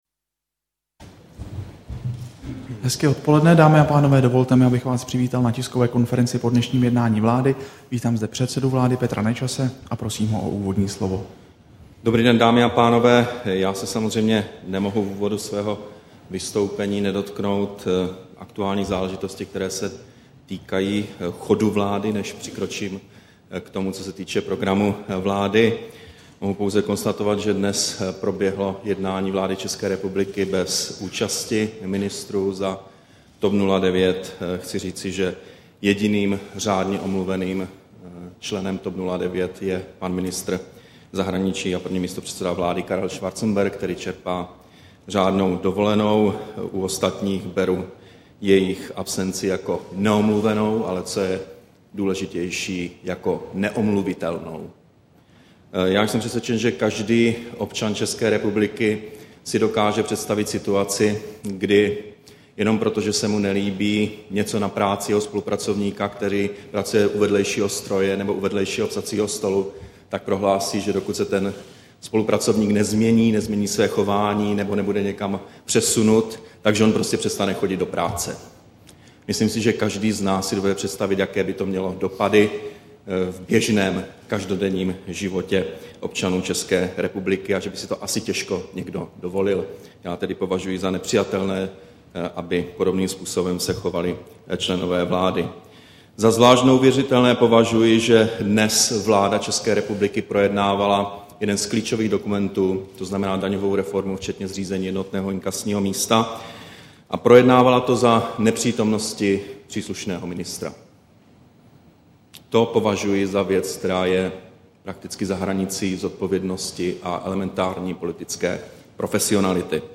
Tisková konference po jednání vlády, 24. srpna 2011